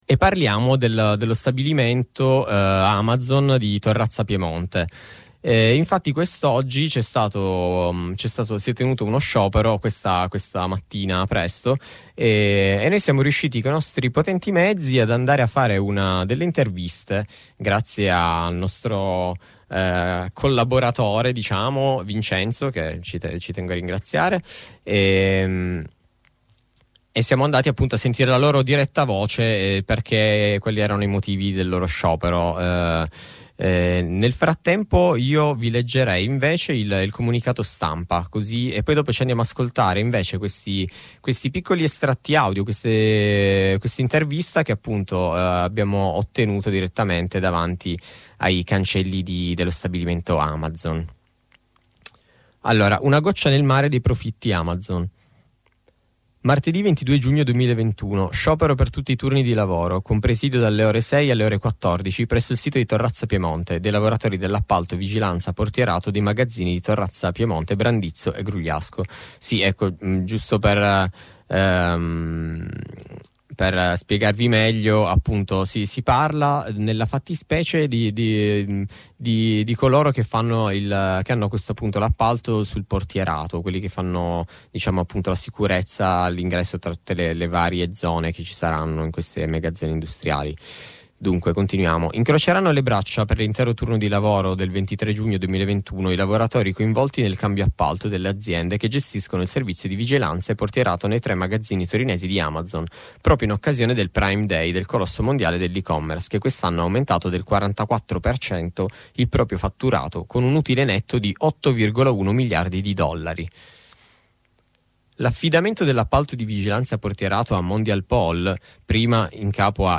Racconteranno tutto ciò: le voci registrate al presidio, la lettura del comunicato stampa dello sciopero ed una breve intervista.
F_m_22_06_Registrazioni-audio-da-presidio-portierato-Amazon.mp3